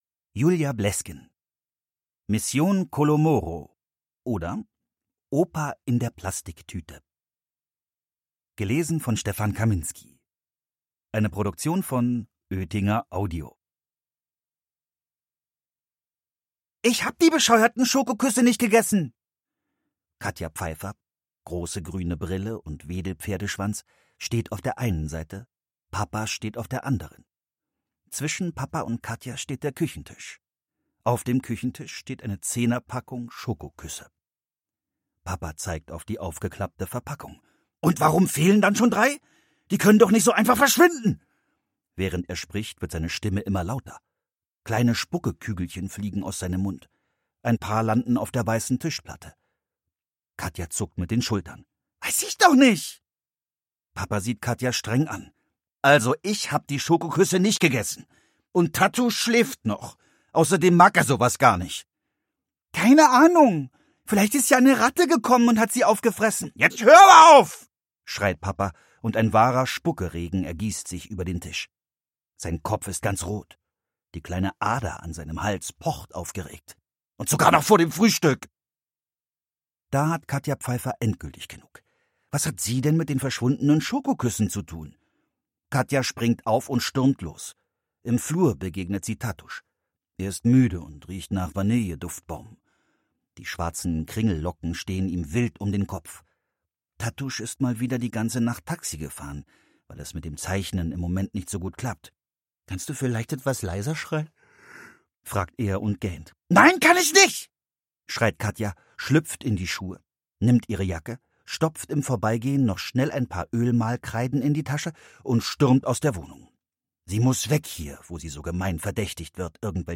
Stefan Kaminski (Sprecher)